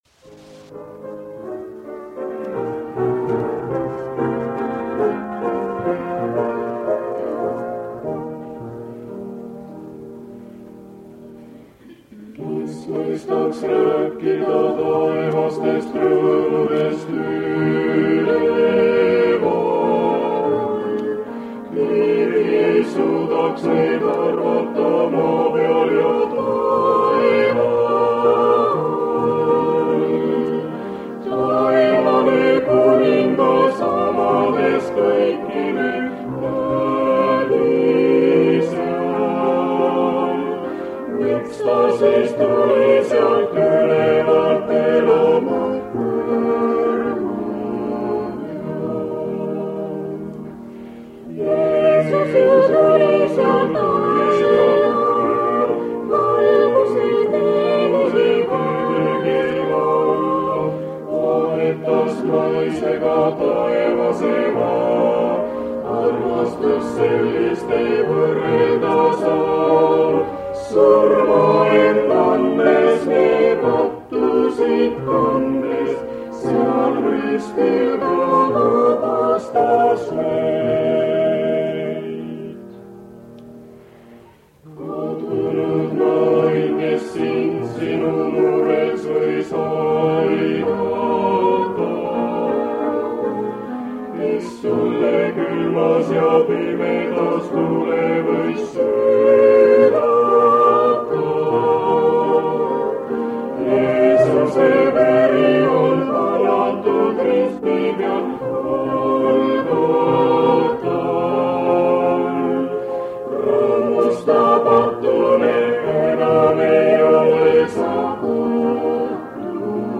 On aasta 1977. Kingissepa linnas Saaremaal (täna Kuressaares) toimub EVANGEELIUMINÄDAL. Siin saame osa hingamispäeva hommikusest õppetükist.